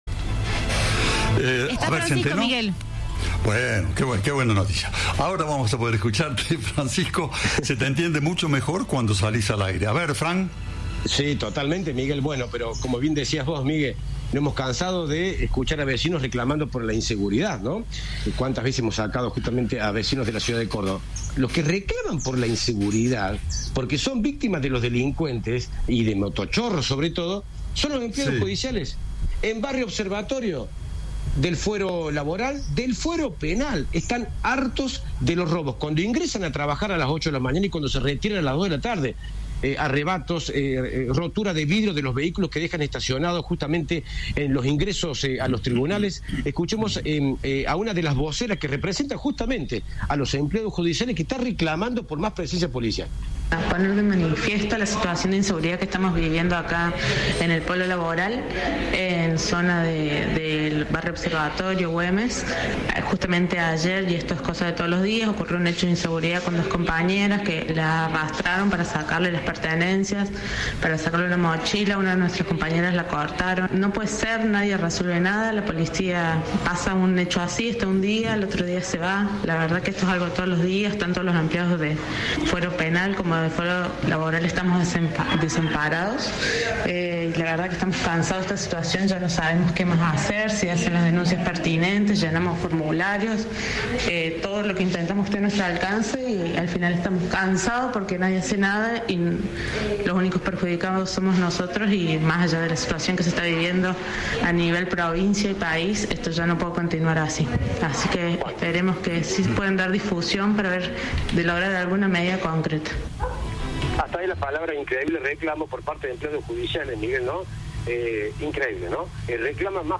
Los trabajadores denunciaron en diálogo con Cadena 3 haber sufrido arrebatos y roturas de los vidrios de sus autos en barrio Observatorio.